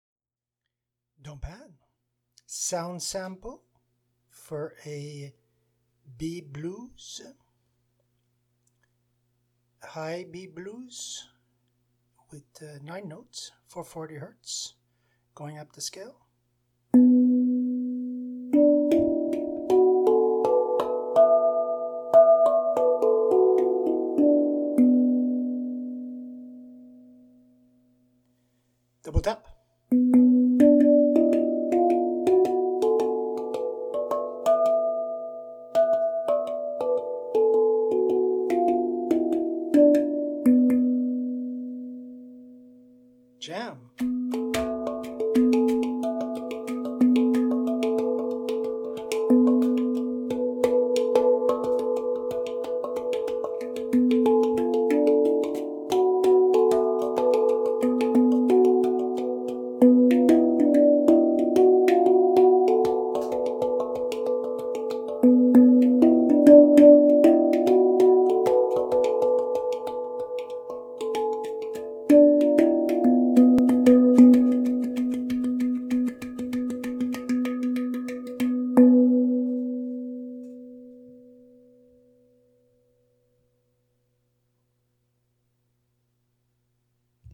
Gorgeous instrument with a deep low B surrounded buy octave 4 to 5, making it a hi-low blues.
Introducing the new Elite Series handpan, boasting a bright sound sustained by nice resonances due to its standard 22 inches diameter.
Enjoy the long sustain on this up beat high B Blues scale that inspires into inspiring new dynamic rhythms :
B3 / D4, E4, F4, F#4, A4, B4, D5, E5